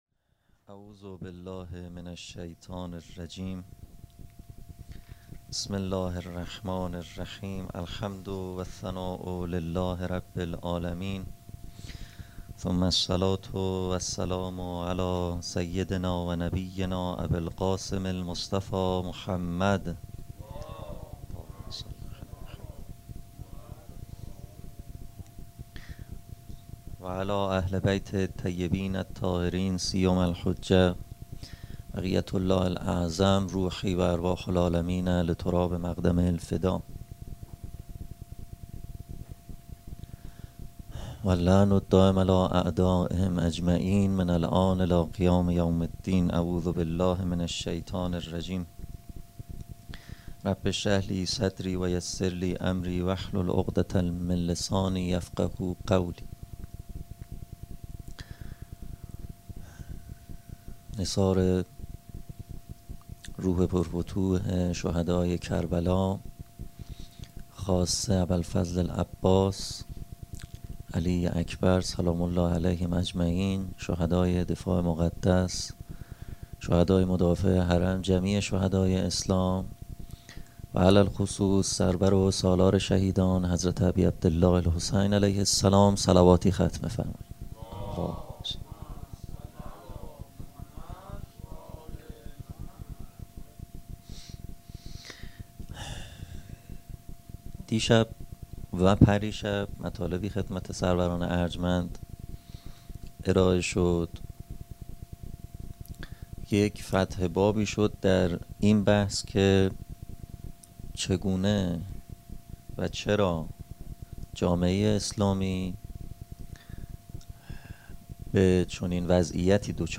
حسینیه بنی فاطمه(س)بیت الشهدا